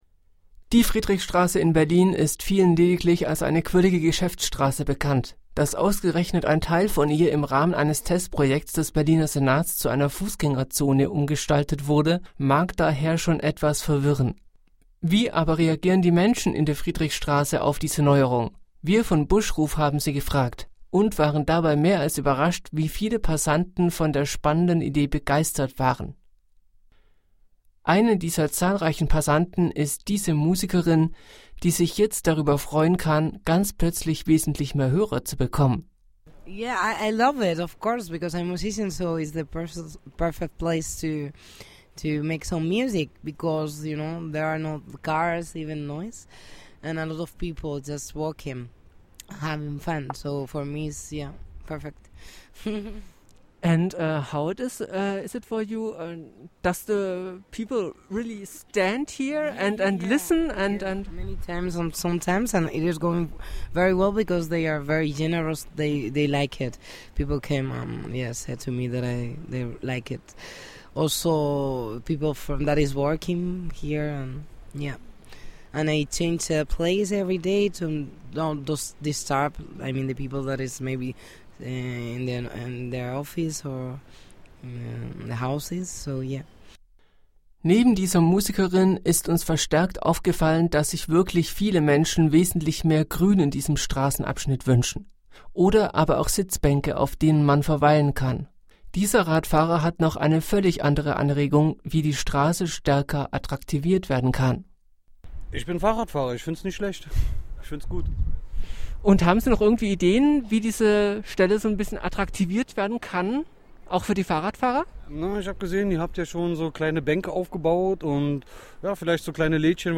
Das Modellprojekt wird ja noch bis in das frühe 2021 fortgesetzt und da werden wir natürlich auch nochmal nachhaken und Nachschau betreiben und die Menschen vor Ort befragen.
Die bisher gemachten Erfahrungen und was die Menschen im Moment zur autofreien Friedrichstraße sagen hören Sie hier in unserem Beitrag!